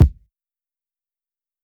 bb - boom bap.wav